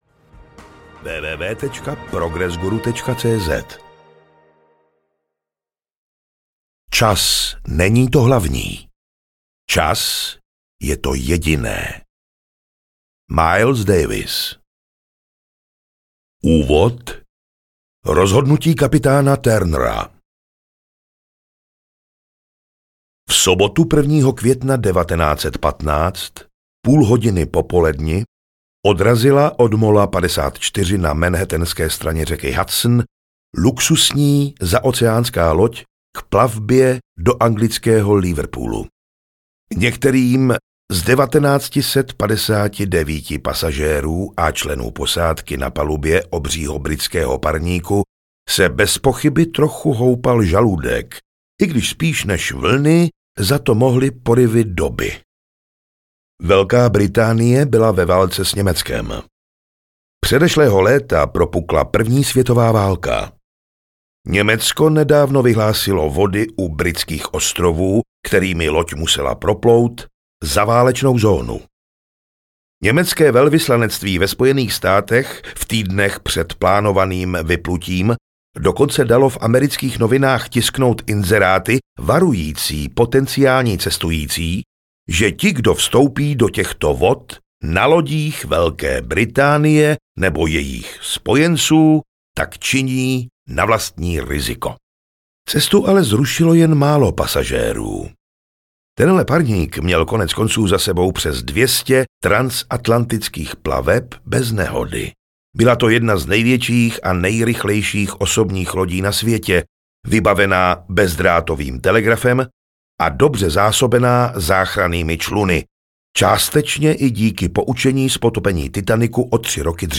Kdy audiokniha
Ukázka z knihy